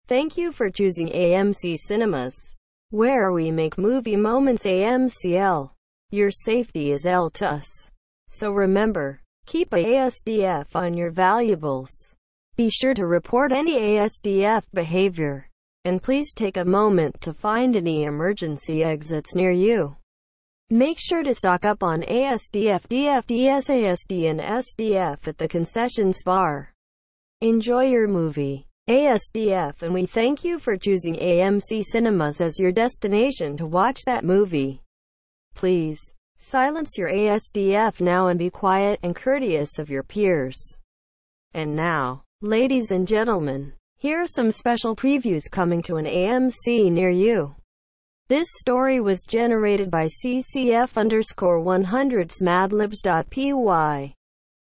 Added TTS support